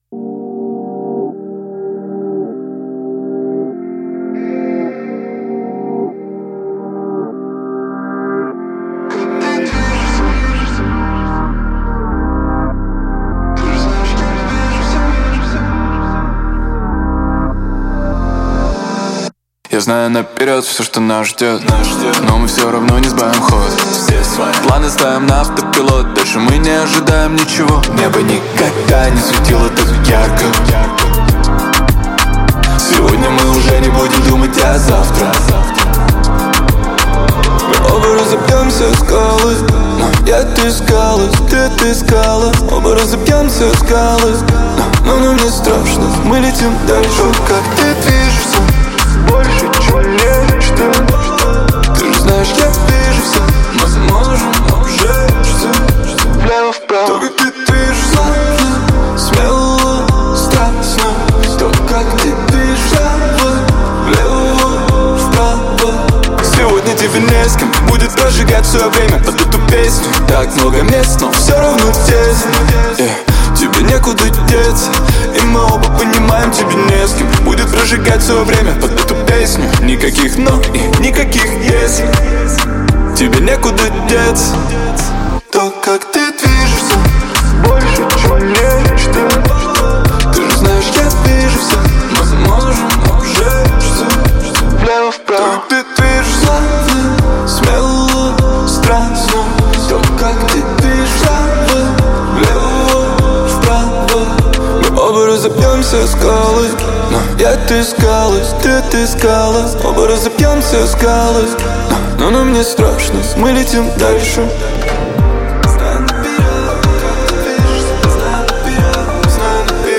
Русский рэп
Жанр: Жанры / Русский рэп